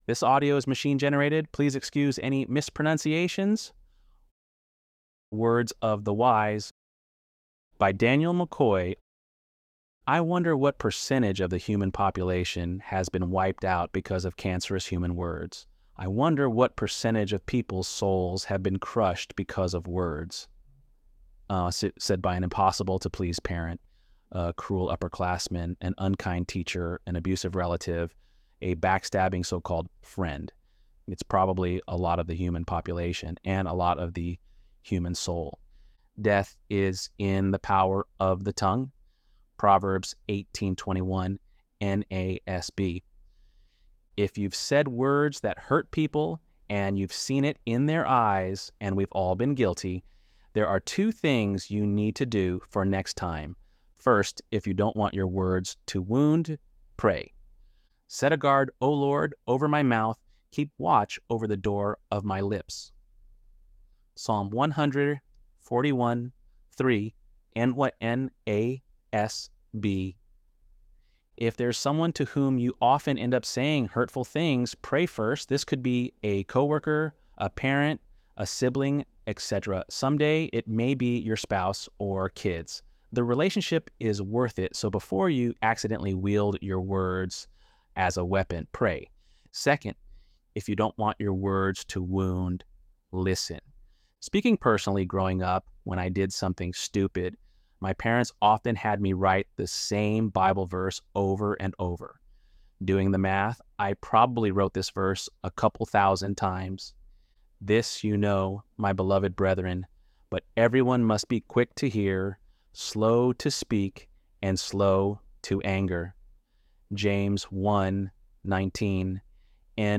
ElevenLabs_1_2.mp3